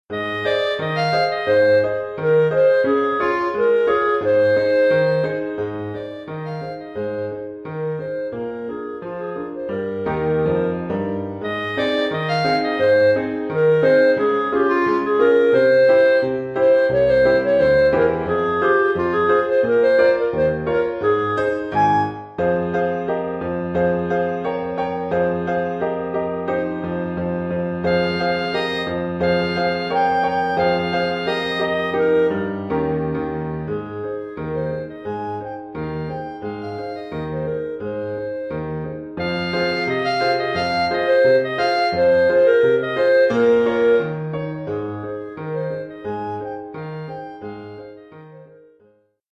Oeuvre pour clarinette sib et piano.